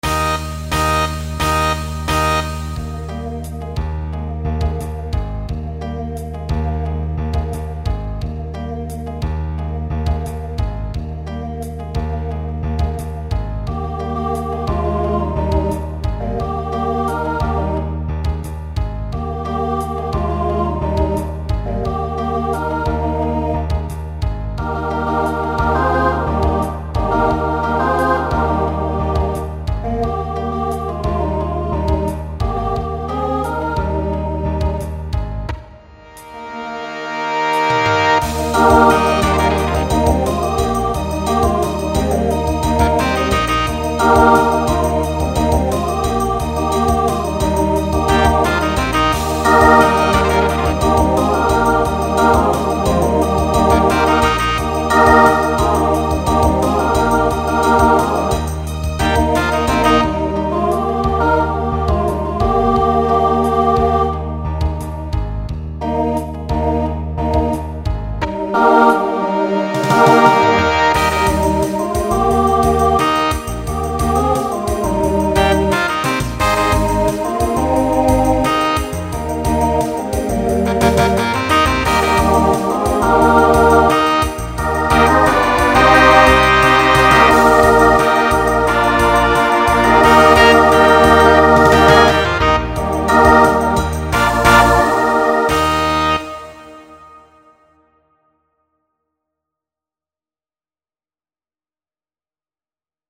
Genre Pop/Dance
Transition Voicing SATB